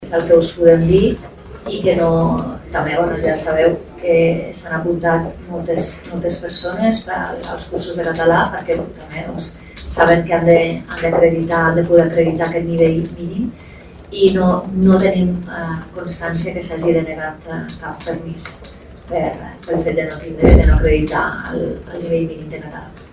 En paral·lel, Bonell ha informat que cap permís d’immigració ha estat denegat per no acreditar el nivell mínim de català.